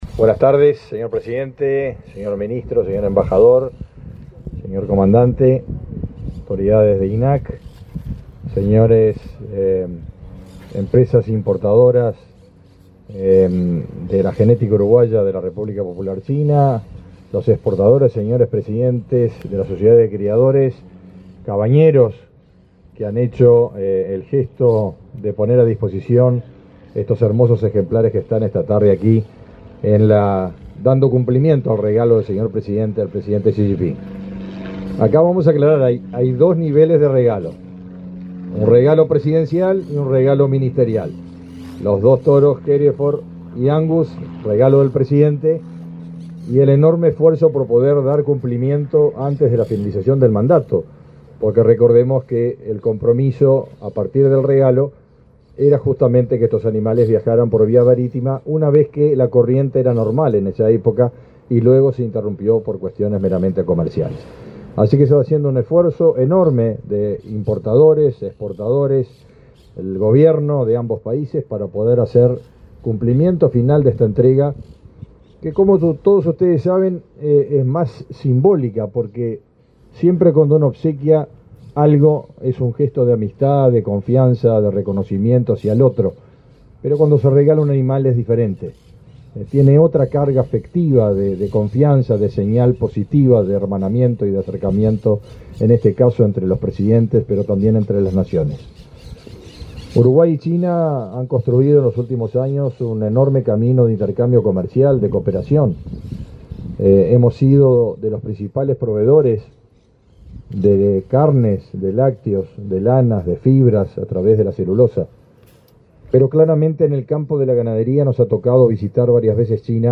Acto por embarque de ganado a China 20/02/2025 Compartir Facebook X Copiar enlace WhatsApp LinkedIn Este 20 de febrero, se realizó el embarque de reproductores de las razas angus, Hereford y bradford con destino a la República Popular China, con la presencia del presidente de la República, Luis Lacalle Pou. En el evento disertaron, el ministro de Ganadería, Agricultura y Pesca, Fernando Mattos, y el embajador del país asiático, Huang Yazhong.